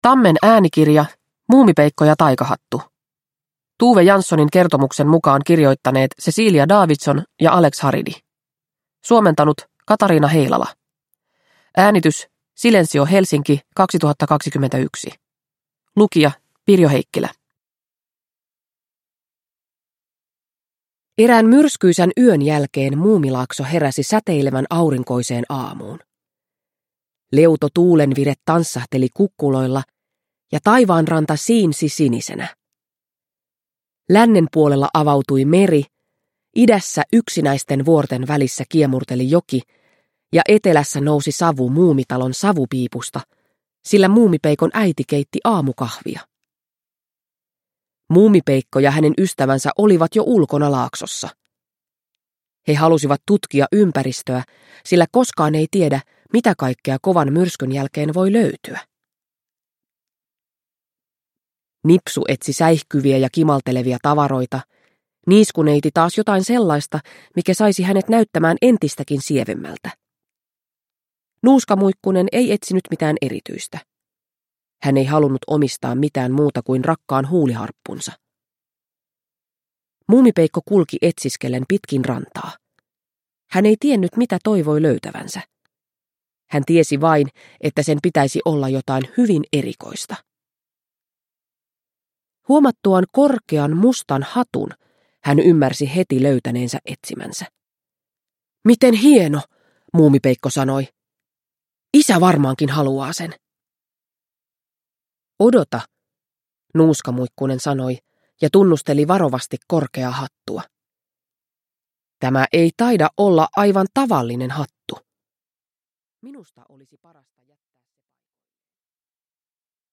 Muumipeikko ja taikahattu – Ljudbok – Laddas ner